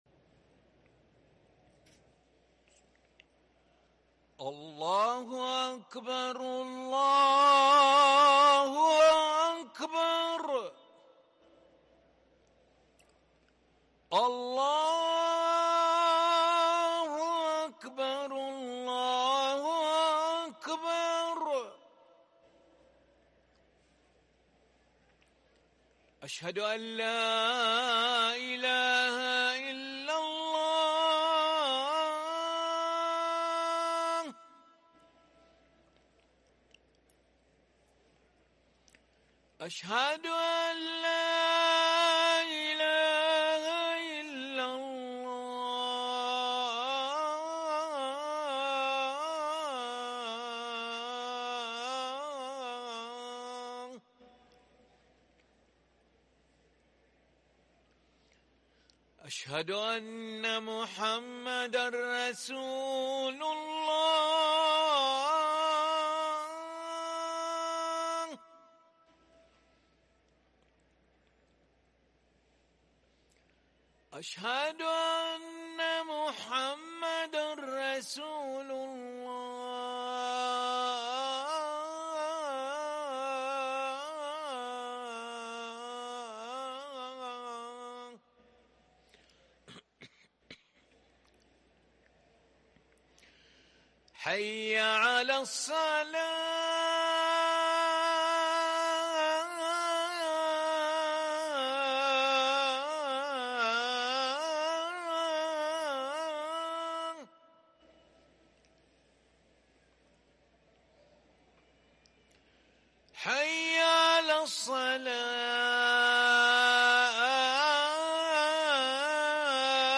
أذان العشاء للمؤذن علي ملا الأحد 23 محرم 1444هـ > ١٤٤٤ 🕋 > ركن الأذان 🕋 > المزيد - تلاوات الحرمين